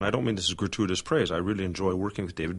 The weak form of as has pretty much disappeared into the final sibilant of this.